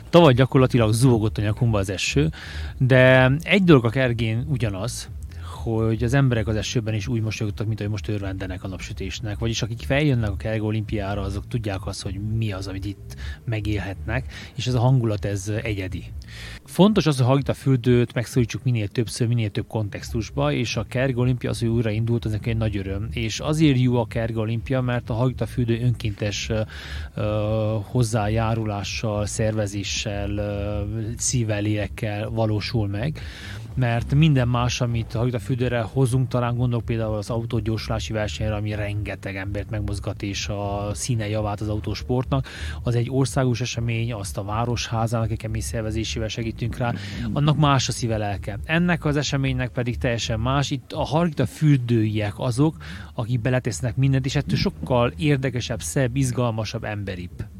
A rendezvény jelentőségéről Korodi Attila, Csíkszereda polgármestere is beszélt.